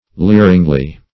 leeringly - definition of leeringly - synonyms, pronunciation, spelling from Free Dictionary Search Result for " leeringly" : The Collaborative International Dictionary of English v.0.48: Leeringly \Leer"ing*ly\, adv.